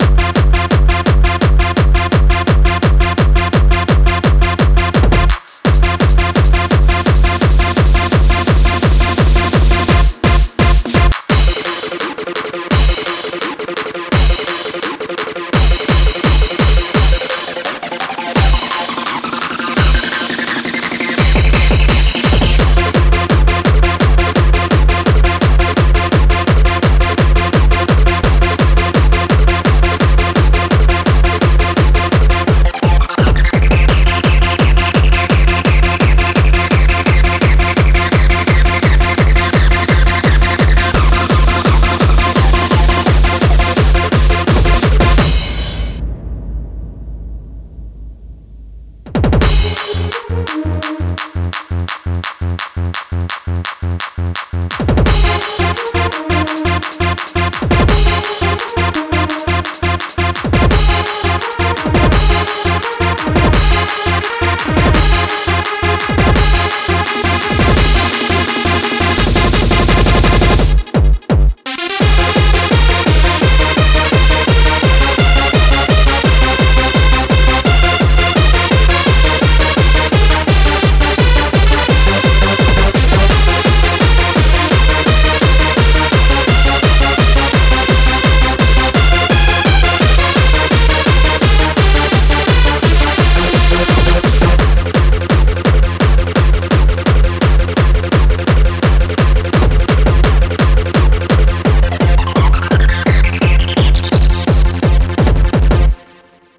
BPM170-170
Audio QualityMusic Cut